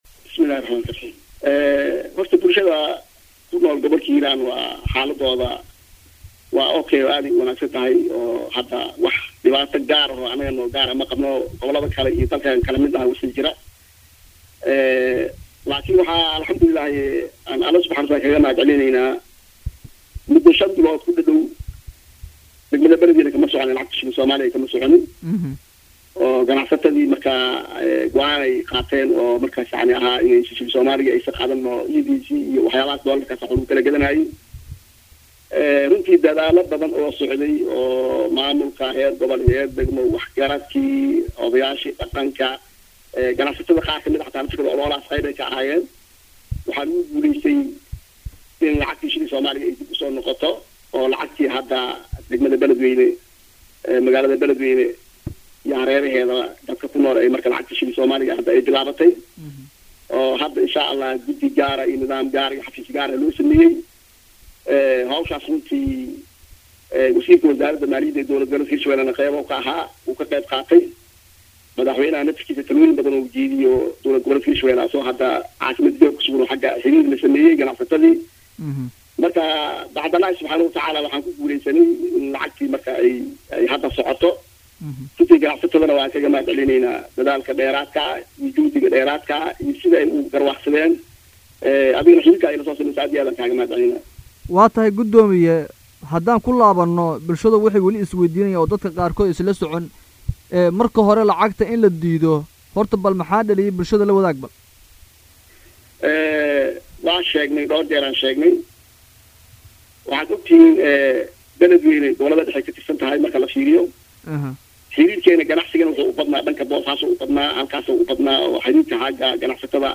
wareysi-Gudoomiye-ku-xigeenka-Arrimaha-Bulshada-Gobolka-Hiiraan-shiikh-xuseen-Cismaan-Cali-.mp3-bbbbbbbbbbbbbbbbbbb.mp3